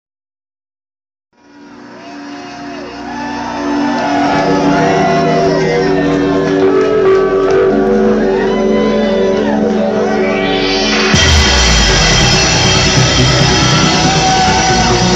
...what a build up!!!!
This was heard in houston, TX @ Gatsby.